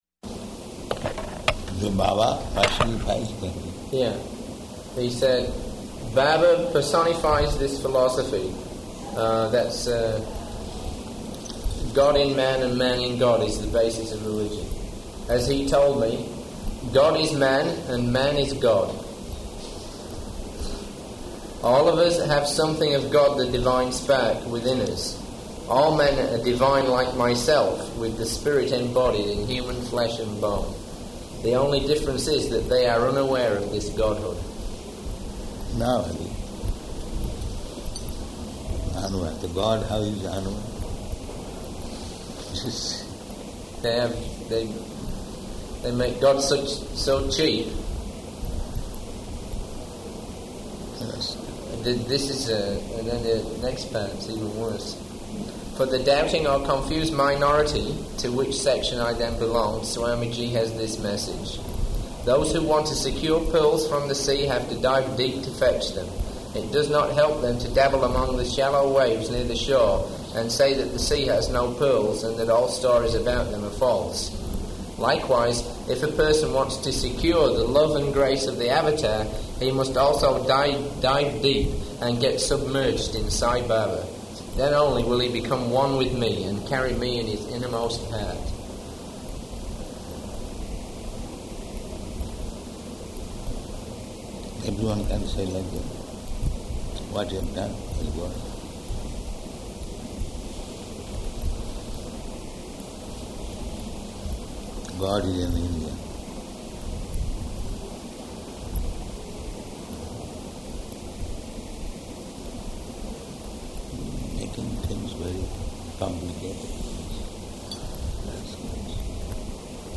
Room Conversation
Location: Vṛndāvana